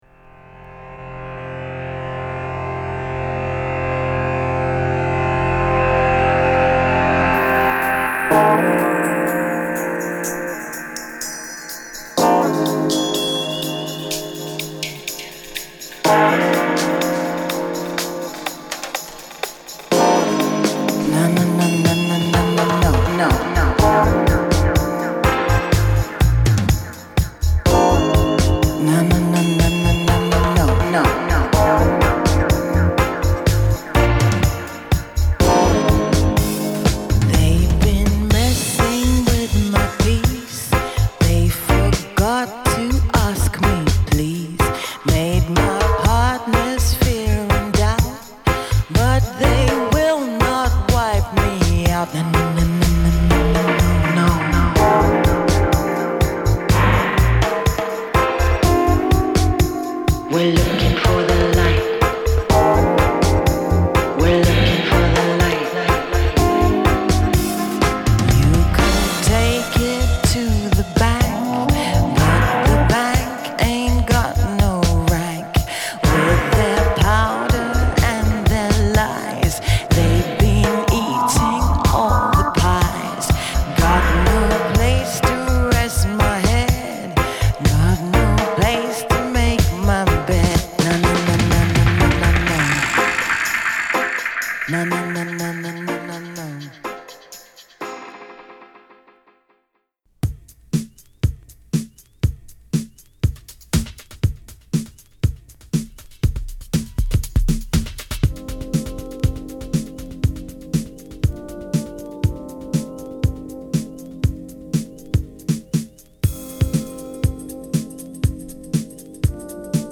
午前3時のダンス・フロアを爆発させるタフなテクノから、バウンシーなエレクトロニカ、酩酊させるダブまで